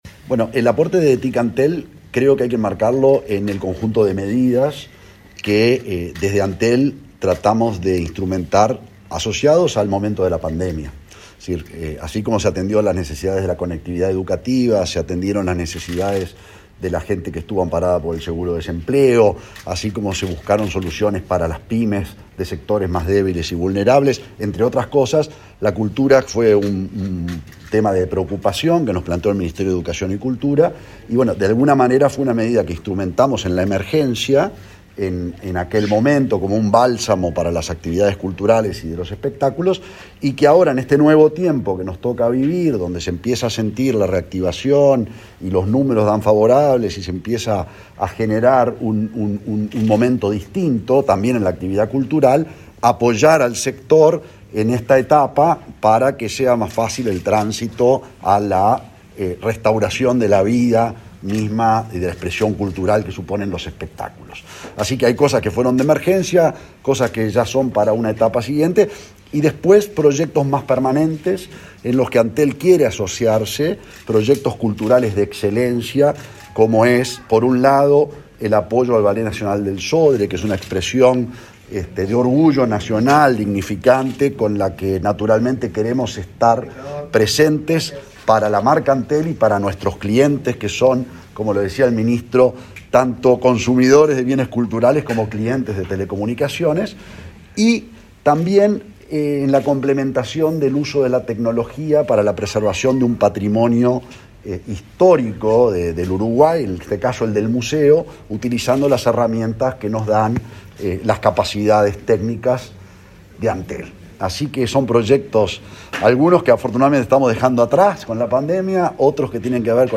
Declaraciones del presidente de Antel y la directora nacional de Cultura
Declaraciones del presidente de Antel y la directora nacional de Cultura 12/08/2021 Compartir Facebook X Copiar enlace WhatsApp LinkedIn Gurméndez y Wainstein dialogaron con los representantes de los medios tras participar, este jueves 12 de agosto, de una conferencia de prensa en la que se anunciaron apoyos al sector cultural.